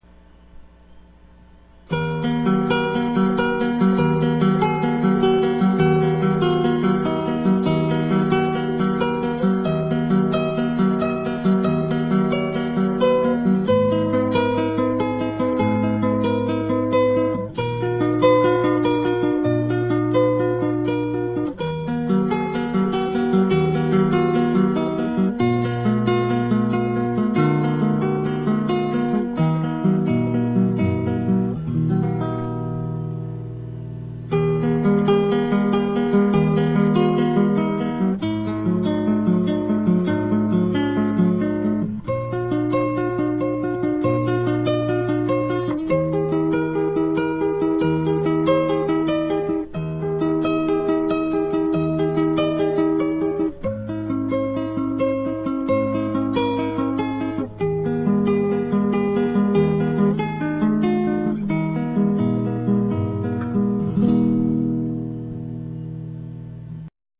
Tuning: EADGBE Key: E minor/E major Sample: MIDI Format,
Recommended for those starting in the classical guitar field.